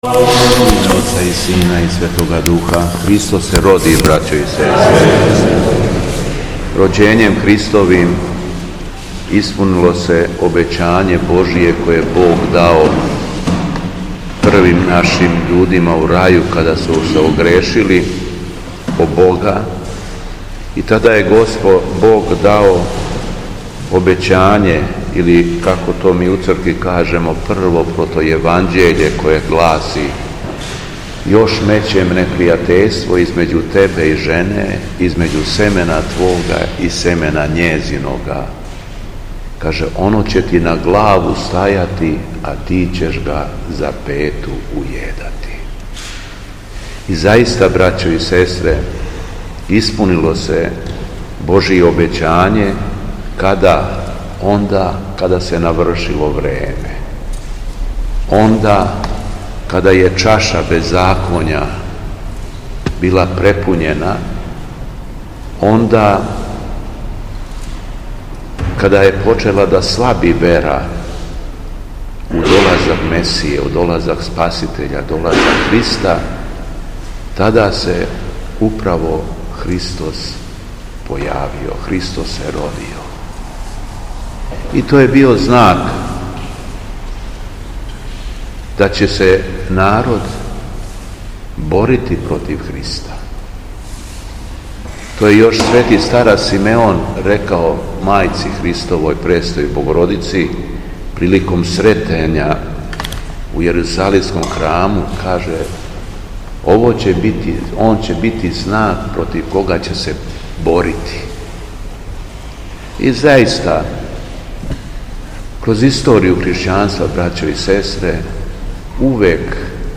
Беседа Његовог Преосвештенства Епископа шумадијског г. Јована
После прочитаног Јеванђеља преосвећени владика се обратио беседом сабраном народу рекавши: